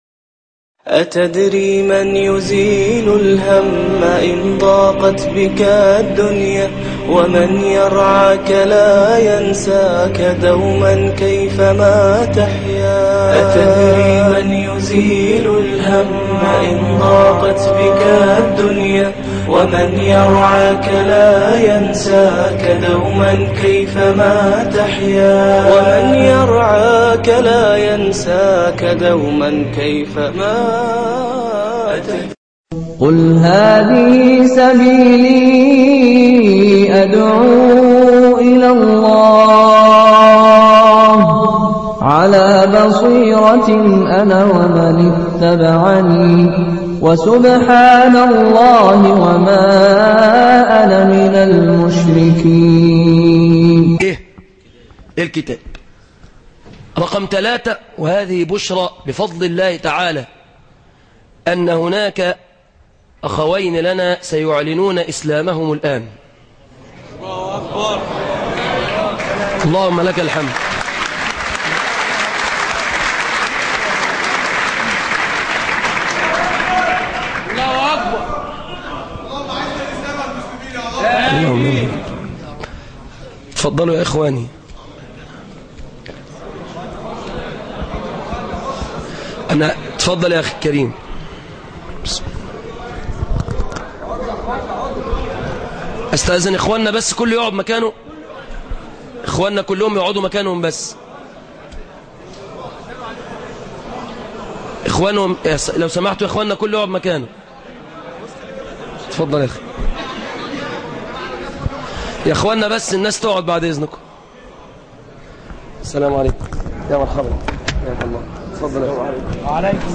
دخول أخين في الإسلام عقب خطبة جمعة